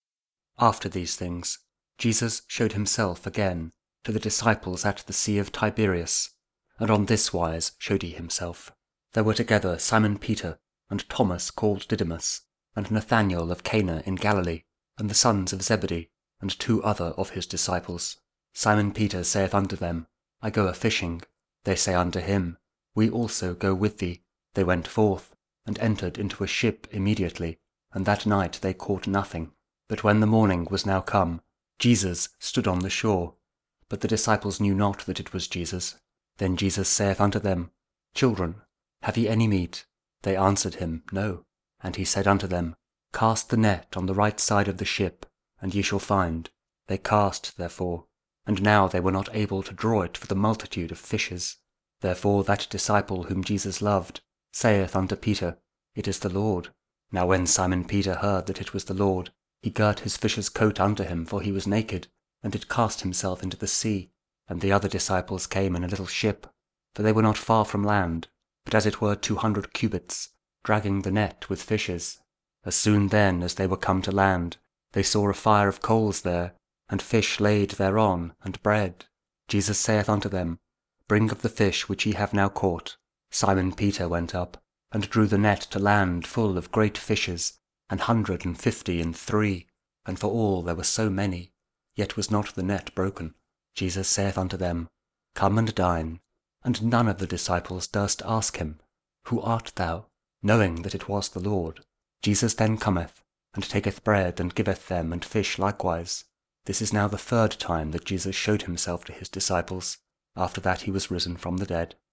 John 21: 1-14 Audio Bible KJV | King James Version | Daily Verses